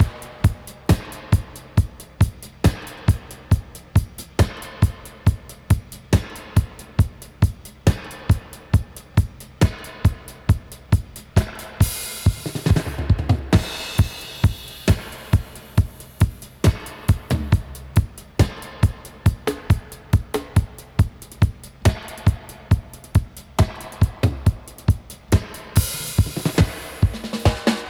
136-DUB-05.wav